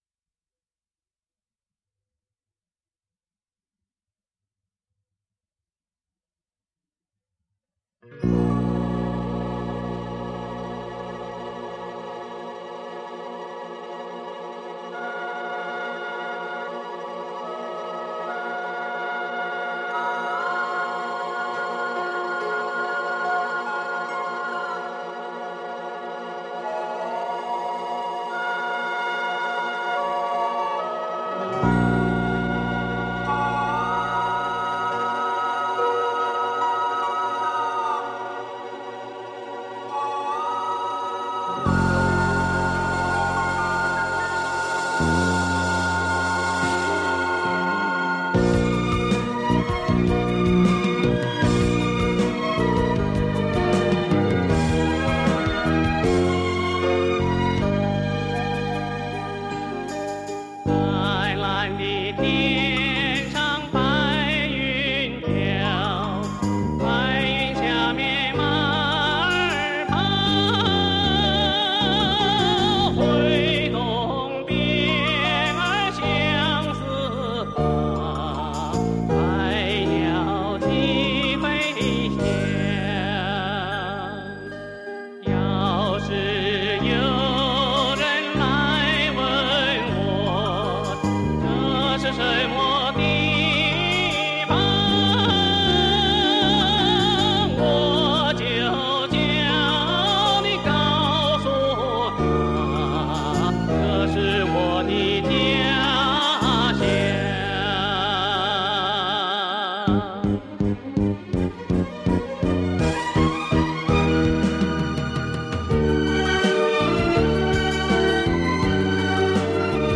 这个系列的磁带制作精良，采用了国内磁带很少使用的杜比B NR及HX PRO技术，音响效果非常不错。
磁带数字化